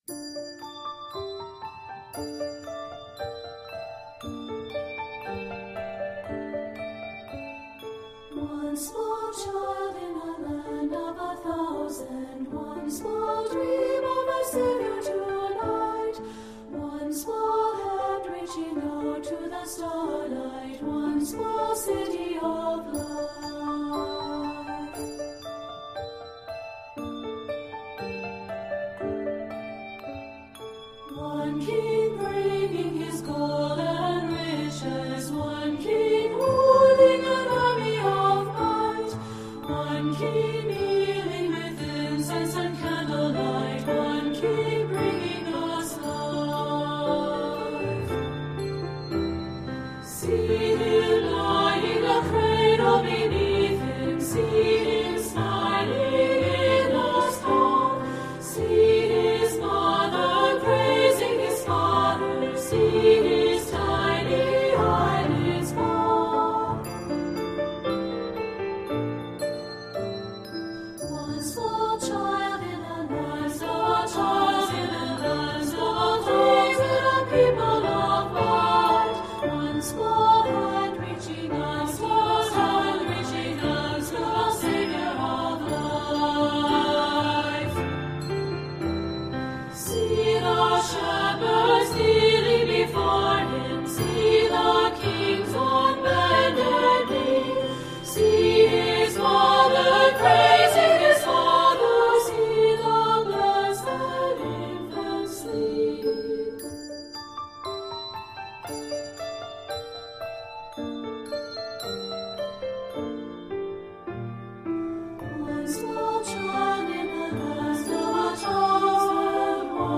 children’s 2-part anthems